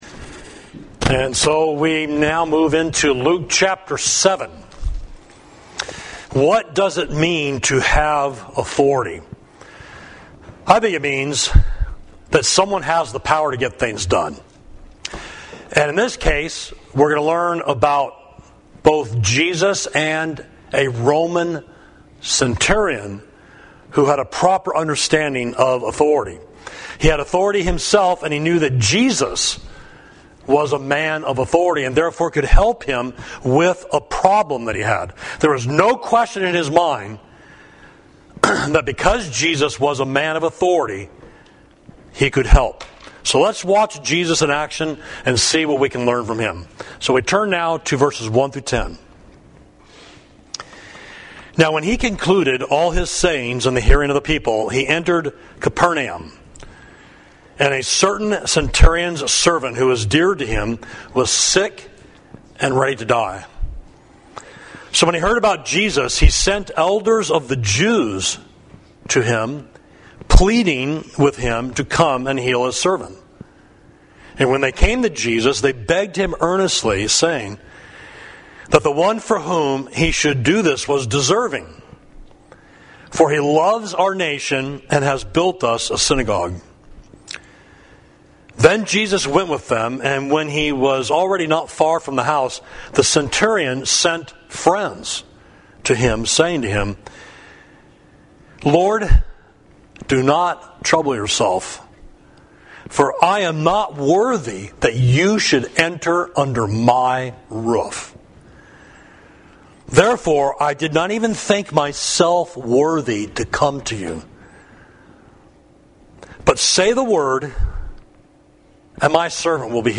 Sermon: A Great Prophet, Luke 7.1–17 – Savage Street Church of Christ